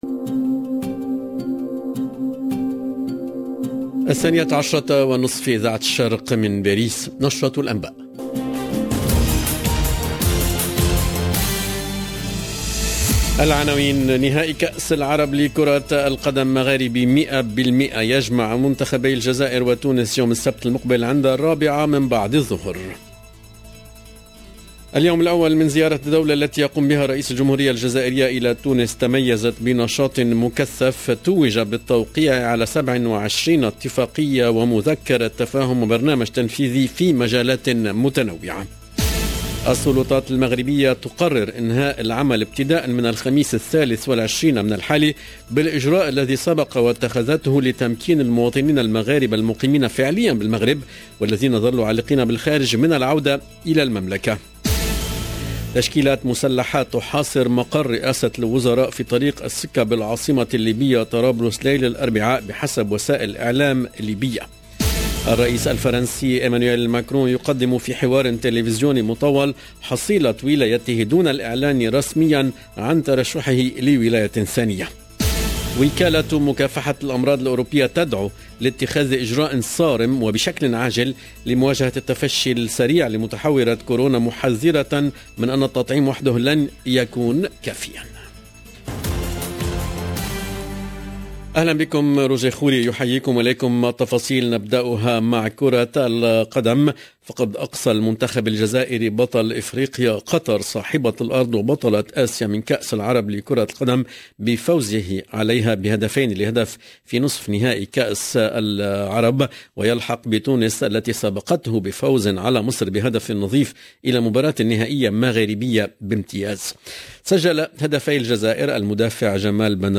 LE JOURNAL EN LANGUE ARABE DE MIDI 30 DU 16/12/21